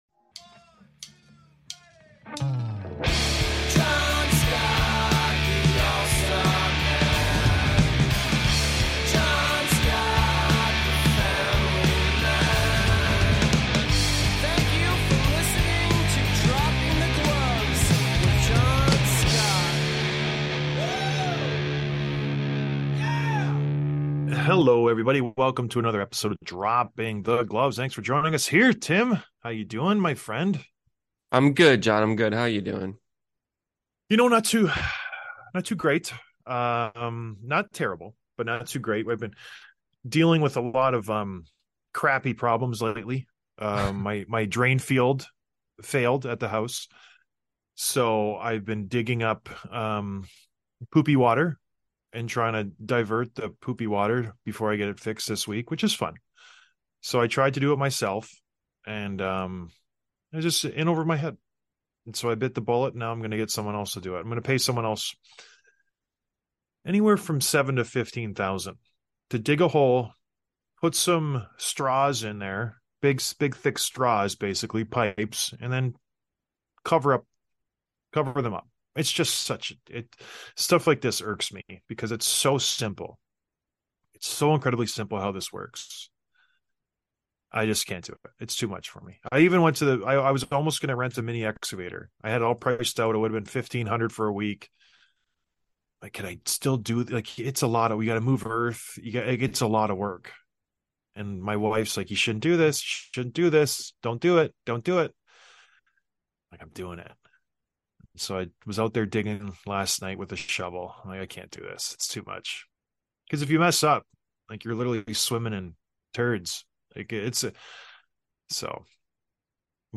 Chicago wins the Bedard sweepstakes, listener voicemails, and recapping the past few days of games. Plus predictions of the Leafs Game 4.